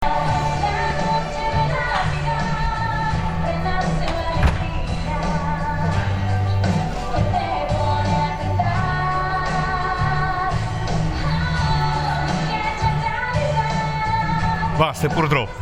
colonna sonora in grado di far scappare chiunque, camperisti compresi, tralasciando ‘chiacchiere da bar’ di ieri pomeriggio, Passo a qualcosa che viene da fuori.
Canzoni-Di-Natale-Davanti-Al-Municipio-del-23-dic.mp3